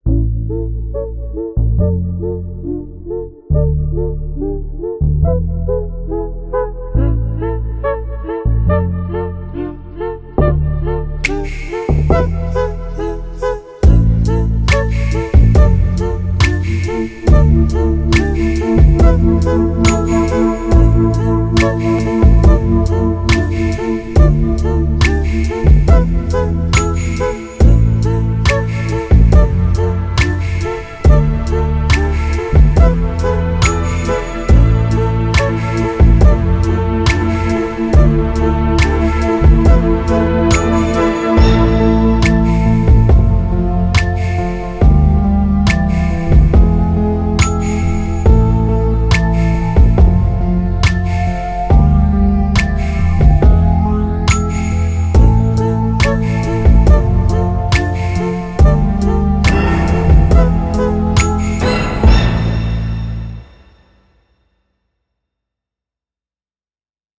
c'est pour accompagner une vidéo lors de remises des prix à un salon du batiment. j'ai utilisé effectivement le sample "orchestral hit", un grand classique des samplers Fairlight dans les années 80.